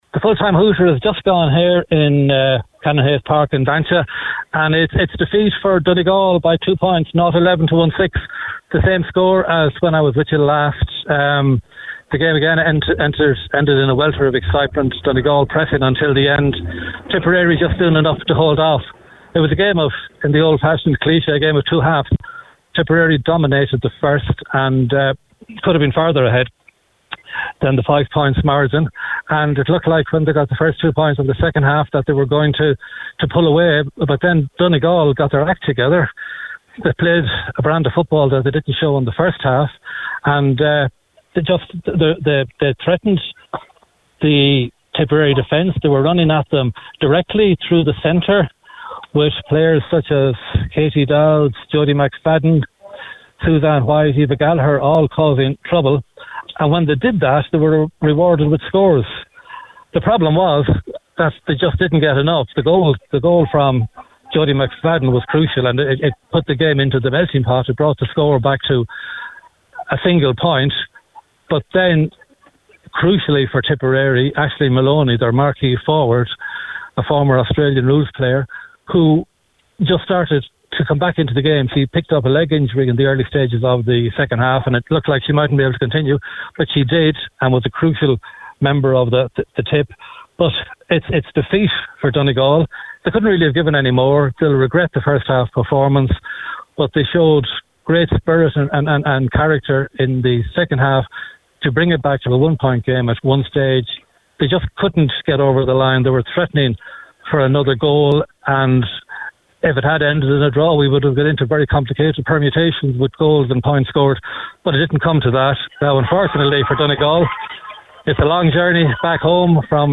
With the full time report for Highland Radio Saturday Sport